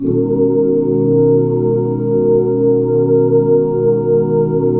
BMAJ7 OOO.wav